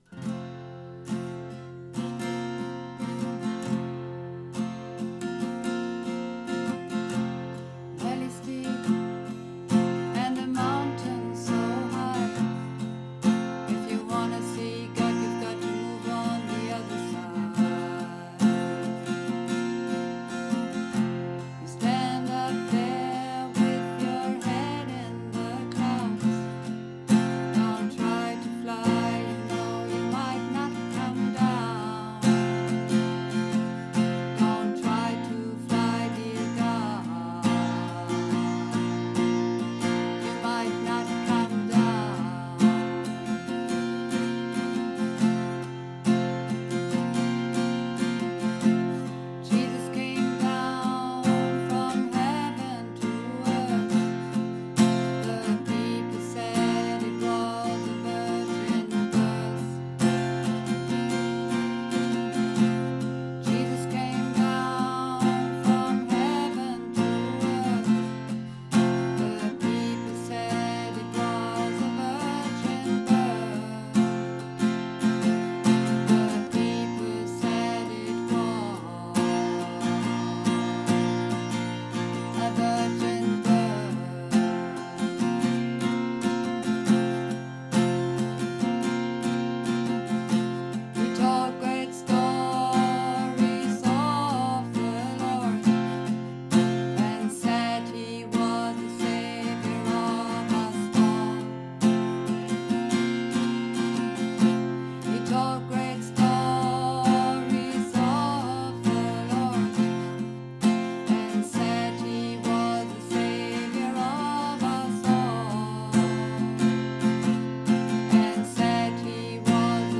Übungsaufnahmen - Hymn
Runterladen (Mit rechter Maustaste anklicken, Menübefehl auswählen)   Hymn 4 Sopran 2 - höher)
Hymn__4_Sopran_2_hoeher.mp3